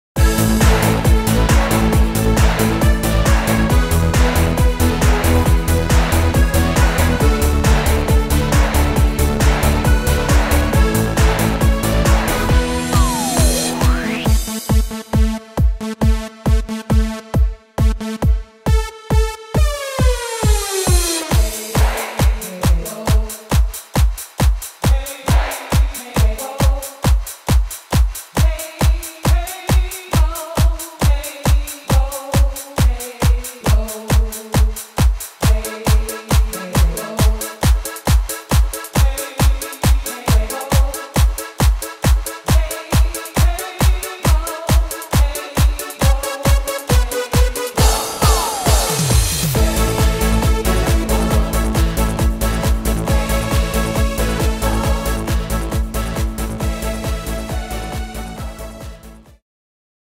Tempo: 136 / Tonart: A-Dur